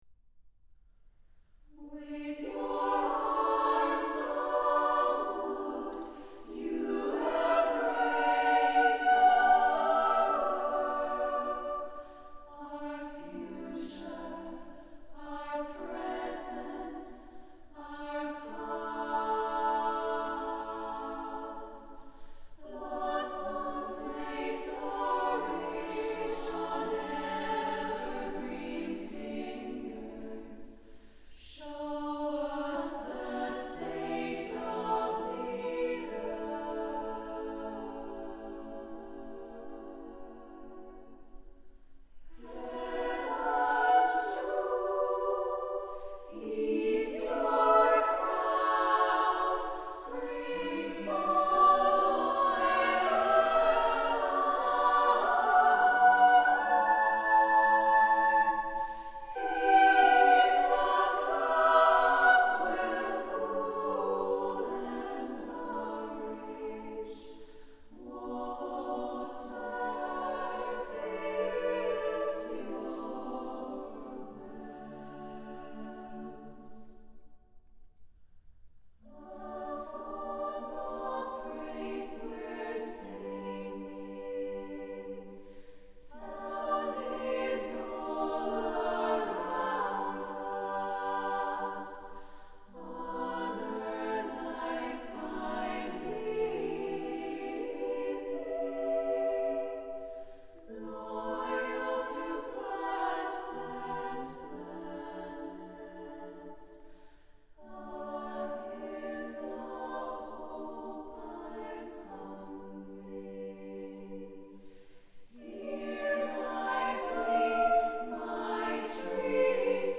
Voicing: "SSAA"